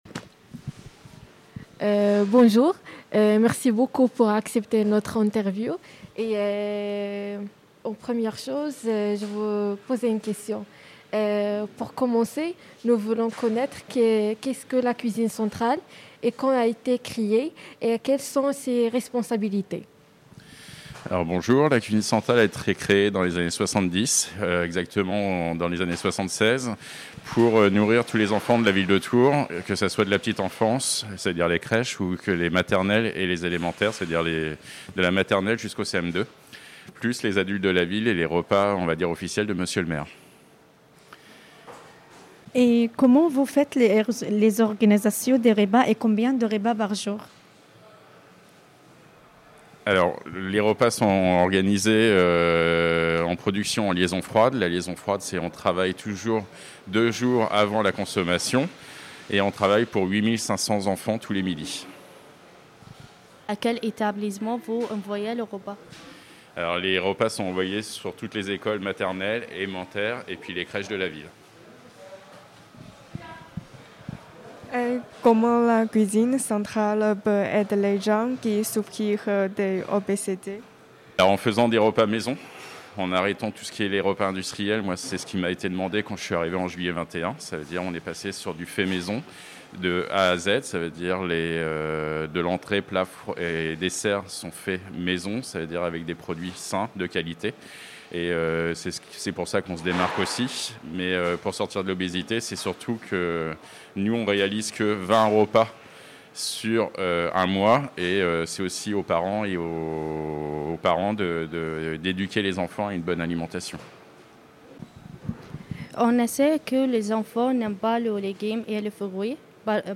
Écouter le reportage à la Cuisine Centrale
reportage-cuisine-centrale.mp3